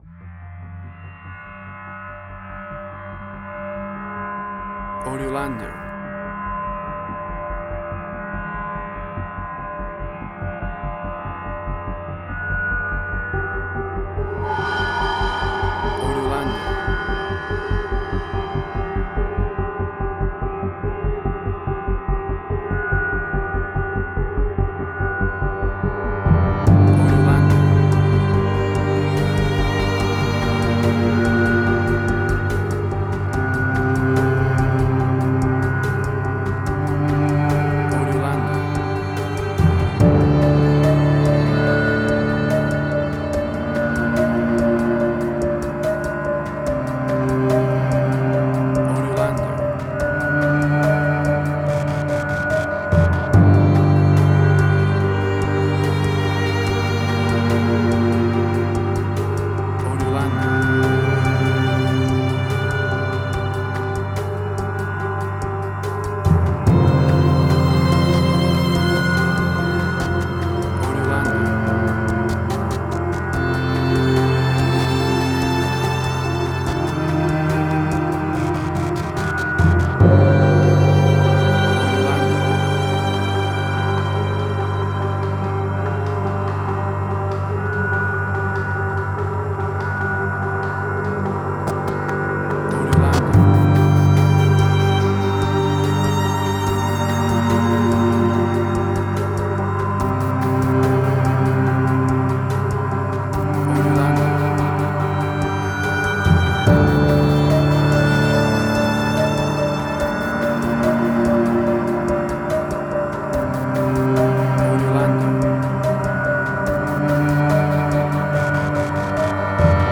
Modern Science Fiction Film, Similar Tron, Legacy Oblivion.
Tempo (BPM): 72